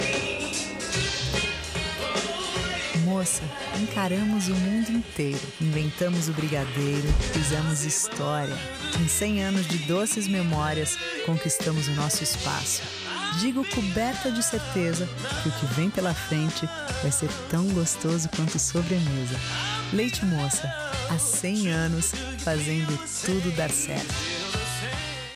Feminino
Voz Madura 00:25